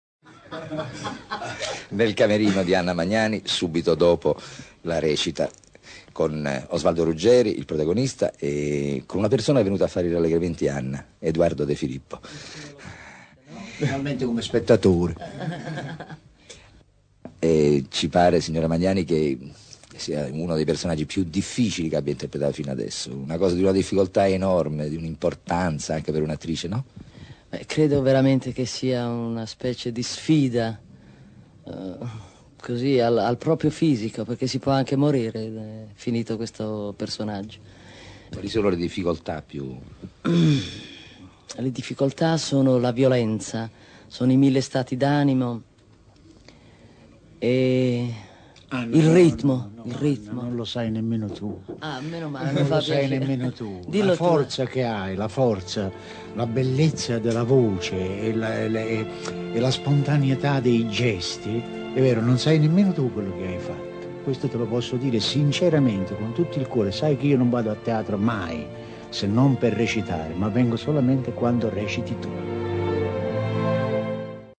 Intervista ad Anna Magnani per “Medea”, 1966.